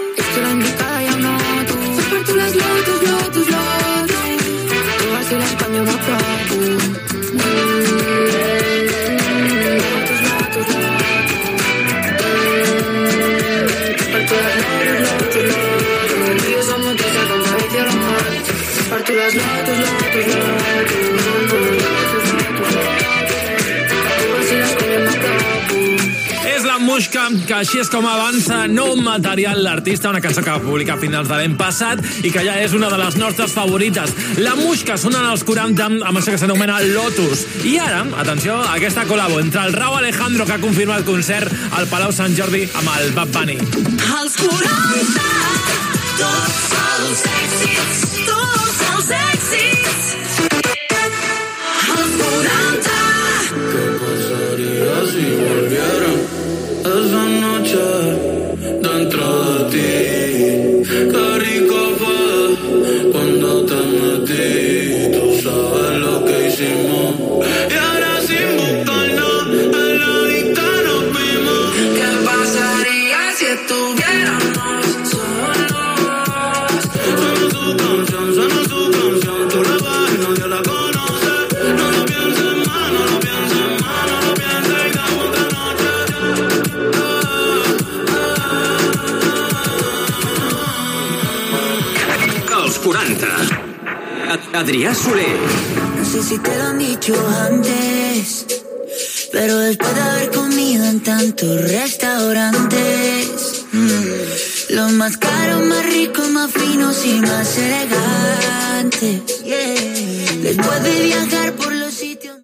Tema musical, comentari sobre el tema que sonava, indicatiu de la ràdio, tema musical, indicatiu del locutot, tema musical
Musical
FM